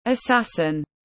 Sát thủ tiếng anh gọi là assassin, phiên âm tiếng anh đọc là /əˈsæs.ɪn/.
Assassin /əˈsæs.ɪn/